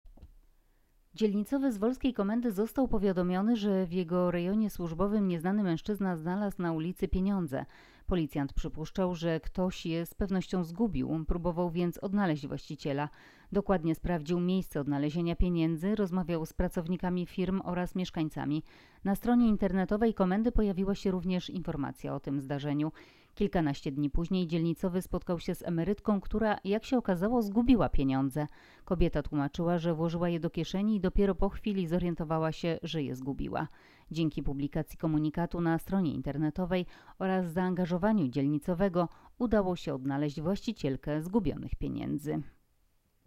Nagranie audio Wypowiedź